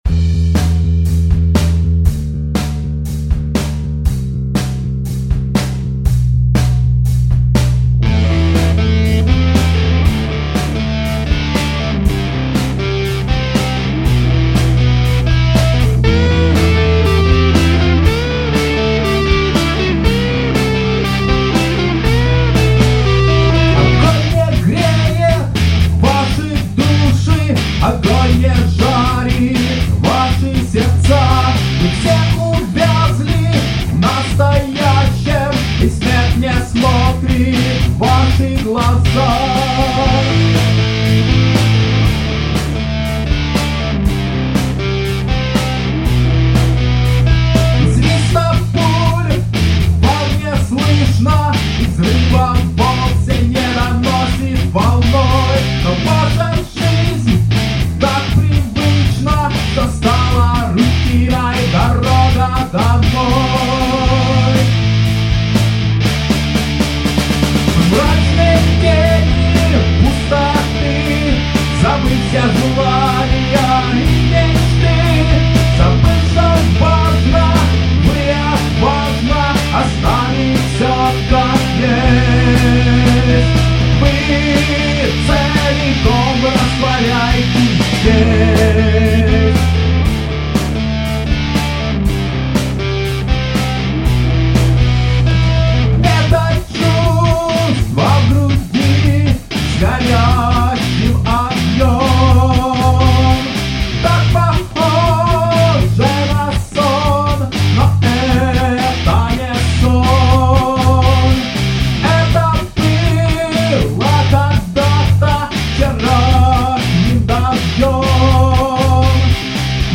вокал
гитара
барабаны
бас
Рубрика: Поезія, Авторська пісня